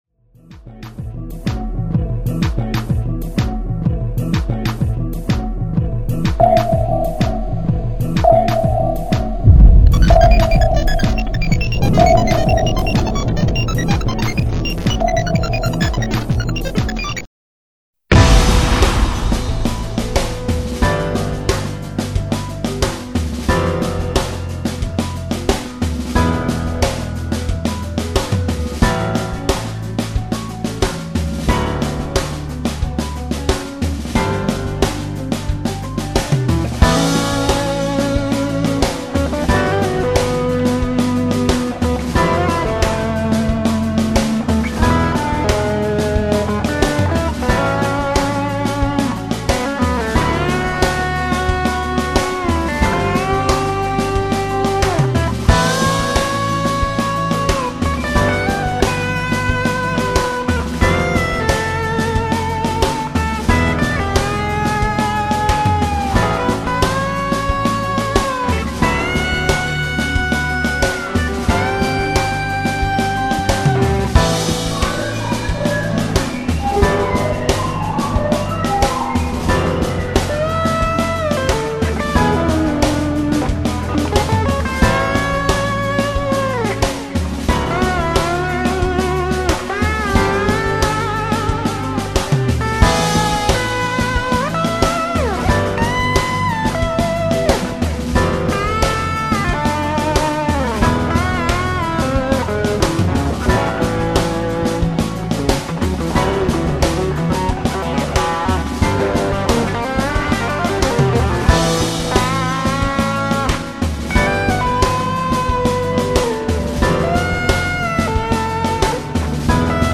oompah and belly dance
Here's an elegaic song to her watery demise.